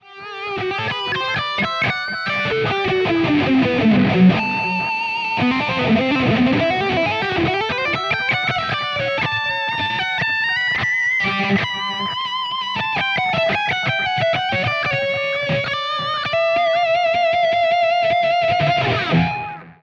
with the stomp and delay on for a little more of a lead feel.